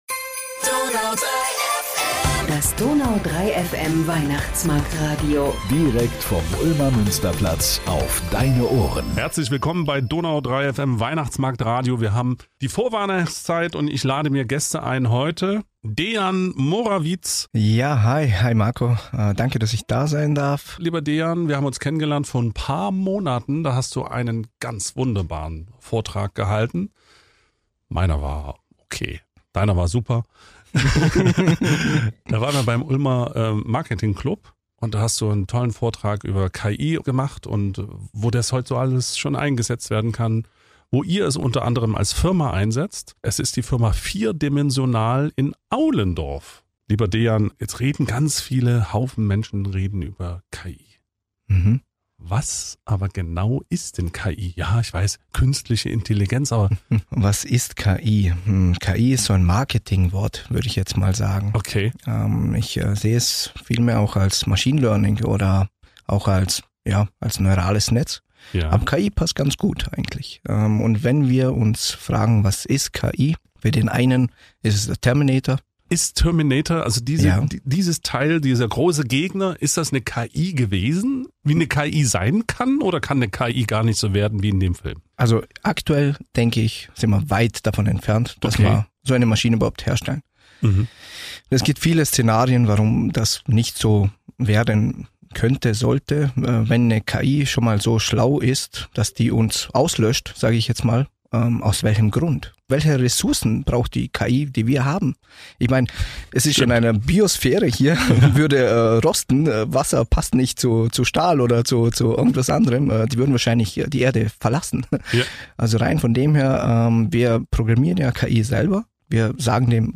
Ulmer Weihnachtsmarktstudio